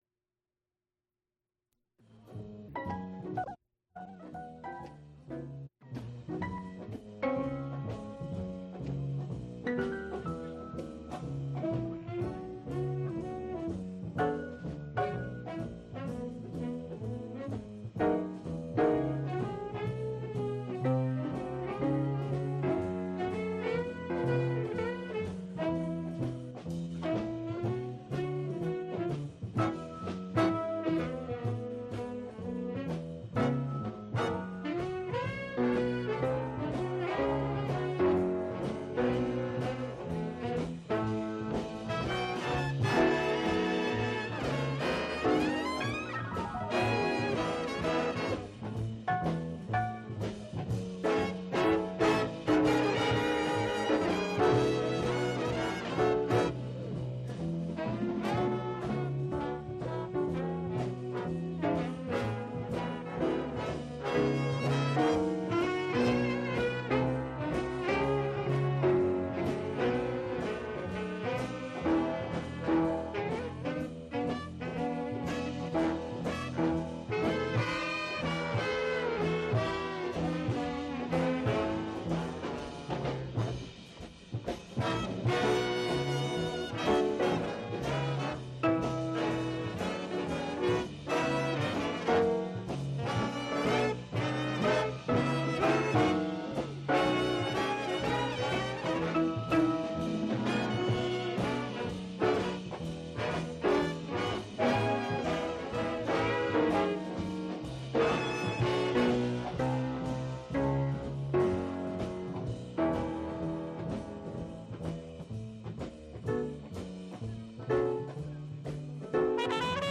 Recorded live at the Three Rivers Arts Festival. Extent 2 audiotape reels : analog, half track, 15 ips ; 12 in.
Jazz--1971-1980